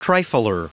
Prononciation du mot trifler en anglais (fichier audio)
Prononciation du mot : trifler